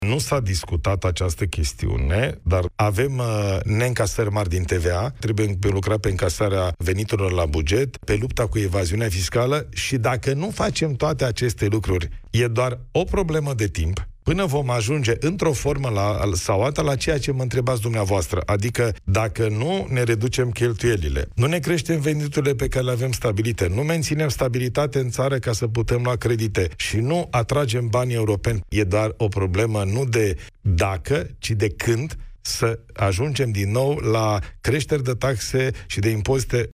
Nu se pune problema dacă se vor mări taxe și impozite, ci când se va întâmpla acest lucru, a spus la Europa FM președintele interimar al PNL – Ilie Bolojan.